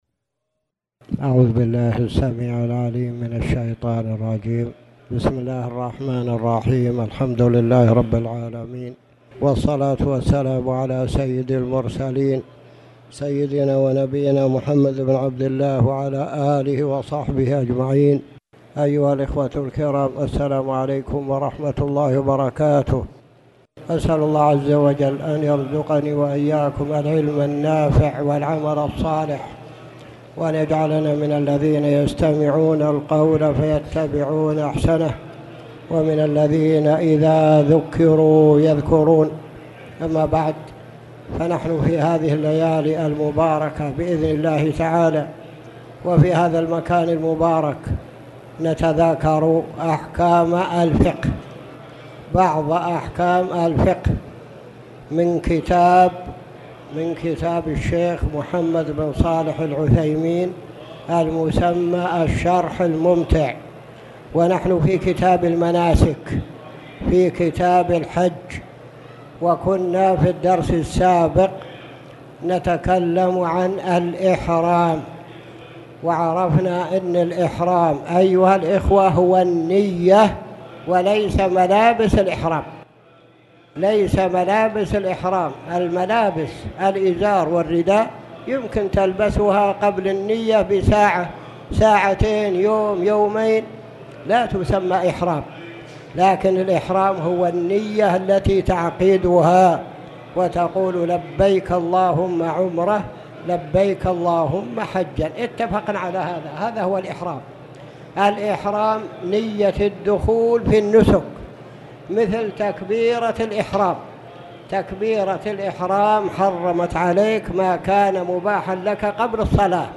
تاريخ النشر ١٠ ذو القعدة ١٤٣٨ هـ المكان: المسجد الحرام الشيخ